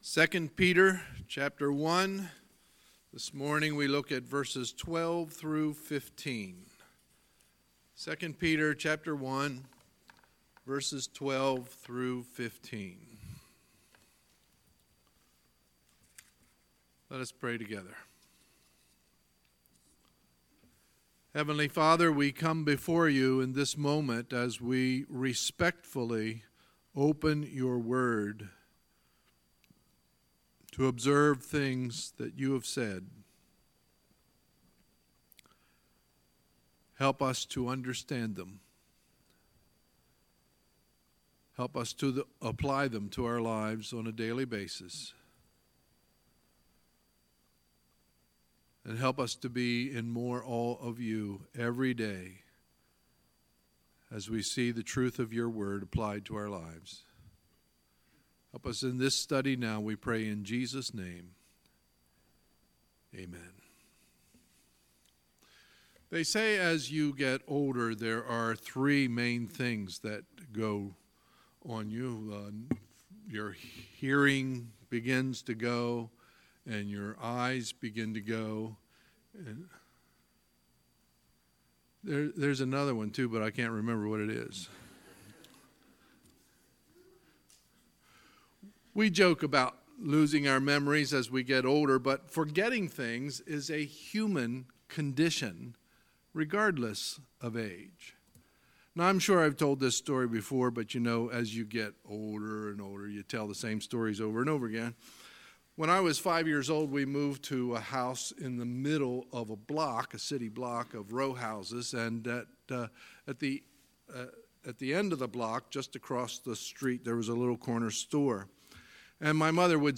Sunday, September 16, 2018 – Sunday Morning Service